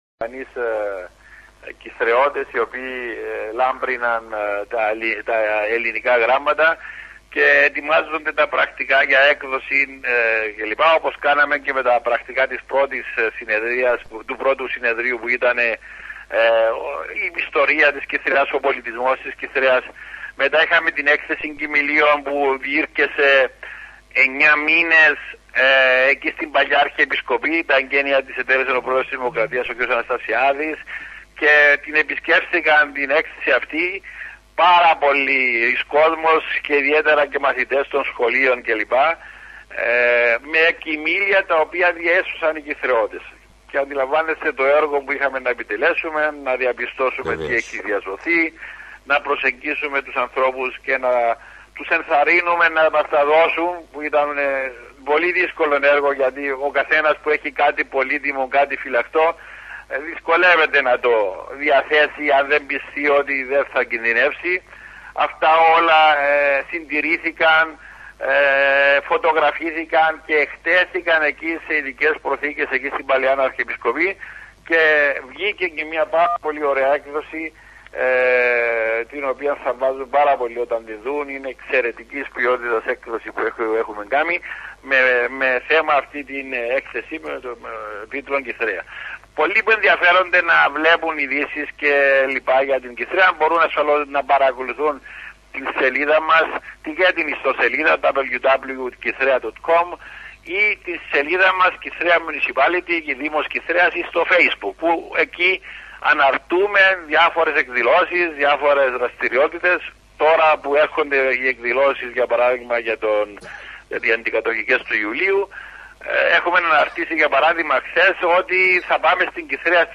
Συνέντευξη Δημάρχου Κυθρέας στην εκπομπή Hellenic Radio Johannesburg
Στο πλαίσιο των διαφωτιστικών δραστηριοτήτων του Δήμου μας, η οποία εκτείνεται και προς τους ομογενείς στο εξωτερικό, ο Δήμαρχος Δρ Πέτρος Καρεκλάς, συμμετέσχε τηλεφωνικά σε εκπομπή του Hellenic Radio στο Johannesburg Νότιας Αφρικής, στις 25 Ιουνίου 2019. Ο Δήμαρχος είχε την ευκαιρία να δώσει κυρίως ενδιαφέρουσες πληροφορίες για τις δραστηριότητες του Δήμου, για την κατεχόμενη σήμερα Κυθρέα και γενικά για το εθνικό μας θέμα, εκφράζοντας τέλος και τις ευχαριστίες μας για τη βοήθεια των απανταχού Ελλήνων στον αγώνα μας για δικαίωση και ελευθερία.